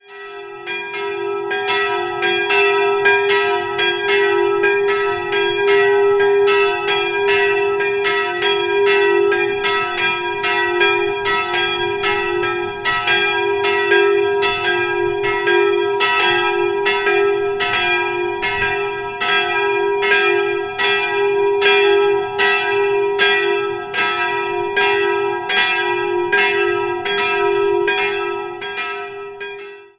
Im Inneren gibt es eine hübsche Barockausstattung mit einer Überraschung: Hans Georg Asam schuf die Altarbilder, am linken Seitenaltar wirkte Cosmas Damian Asam mit. 2-stimmiges Geläut: e''-g'' Die größere Glocke wurde 1761 von Kissner in Stadtamhof gegossen, die kleinere ist unbezeichnet und stammt aus dem 14. Jahrhundert.